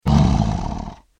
جلوه های صوتی
دانلود صدای شیر 2 از ساعد نیوز با لینک مستقیم و کیفیت بالا
برچسب: دانلود آهنگ های افکت صوتی انسان و موجودات زنده دانلود آلبوم صدای انواع شیر از افکت صوتی انسان و موجودات زنده